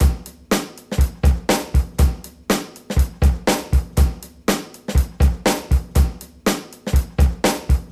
• 121 Bpm Drum Groove C Key.wav
Free drum groove - kick tuned to the C note.
121-bpm-drum-groove-c-key-s6M.wav